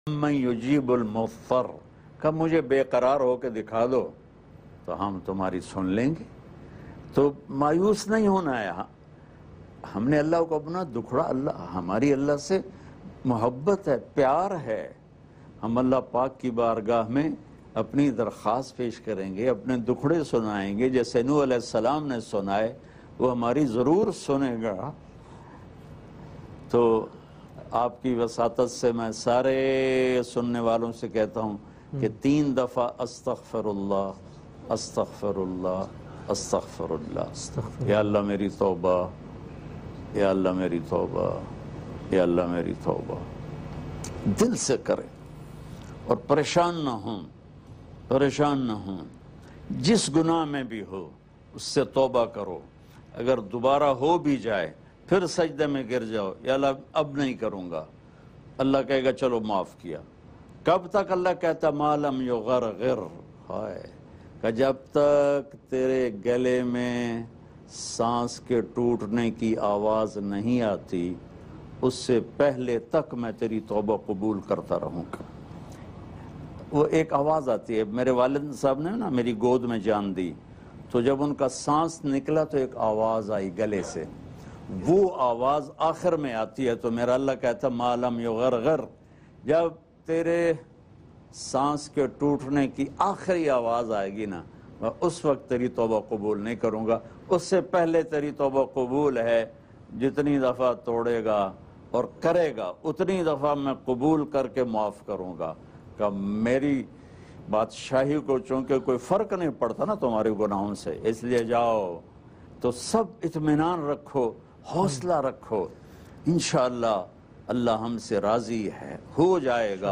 Dua Molana Tariq Jameel Shab e Miraj Emotional MP3 Download
Heart-Touching-Dua-by-Molana-Tariq-Jamil-Shab-eMiraj-2020.mp3